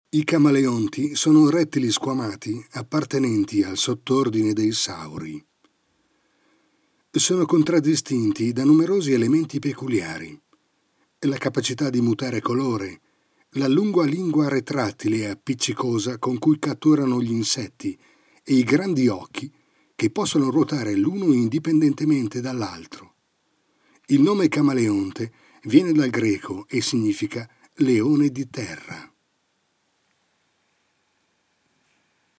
Speaker, voiceover, documentari
Kein Dialekt
Sprechprobe: eLearning (Muttersprache):